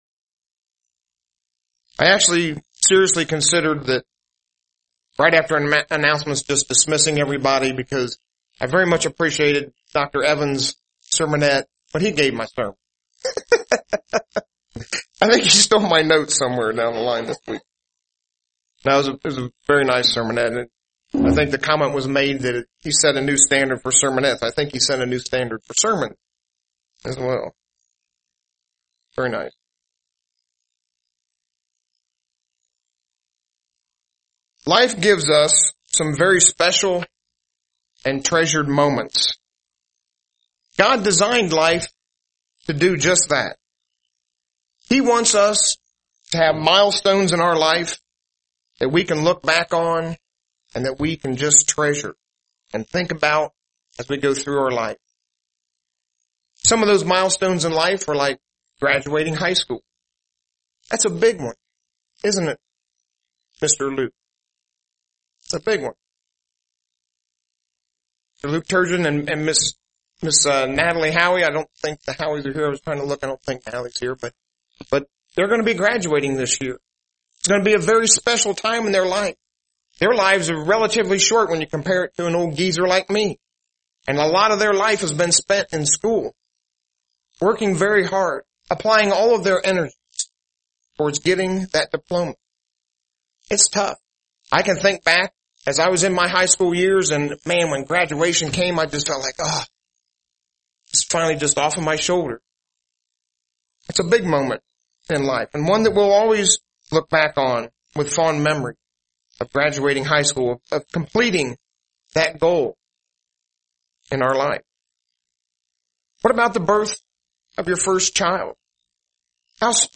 UCG Sermon Notes Notes: Everything that is taking place in our life, is from the hand of God. John 6:44 → No one can come to me with God. 4 Components of Baptism Repentance It means to change one’s mind.